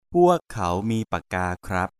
M